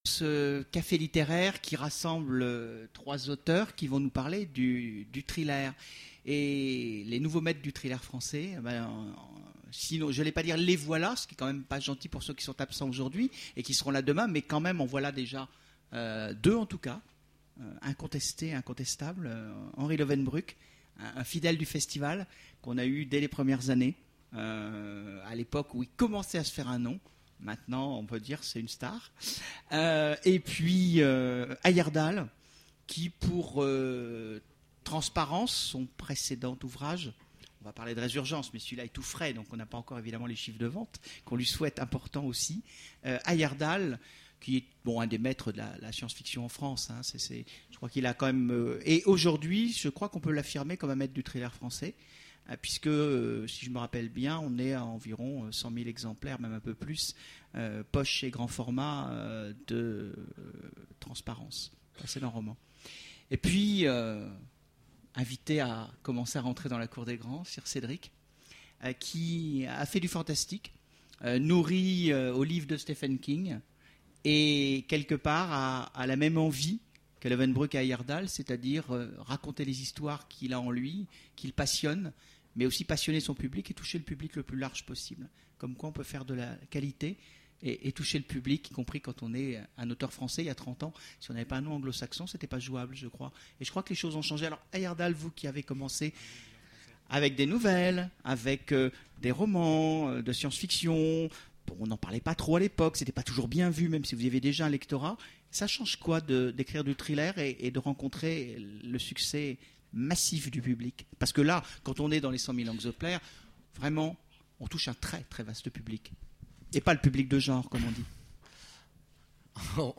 Conférence Imaginales : Les maîtres du thriller français
Voici l'enregistrement de la conférence Les maîtres du thriller français aux Imaginales 2010